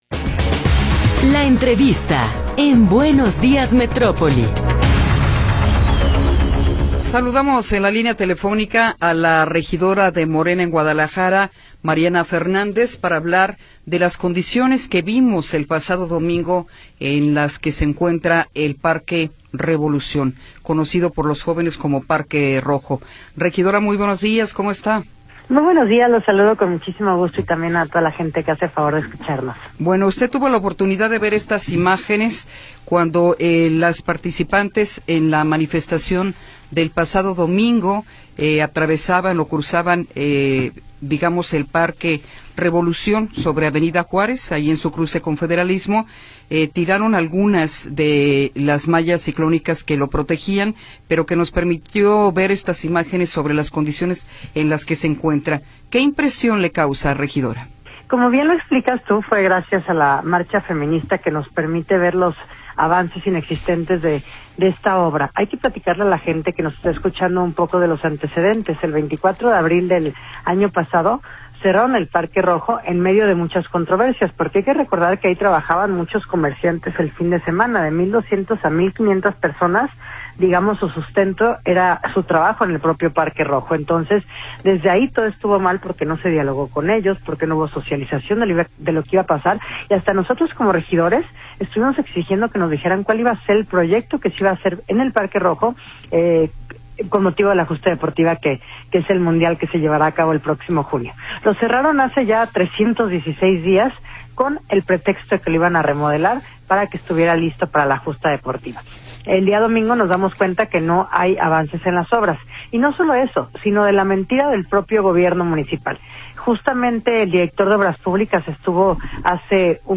Entrevista con Mariana Fernández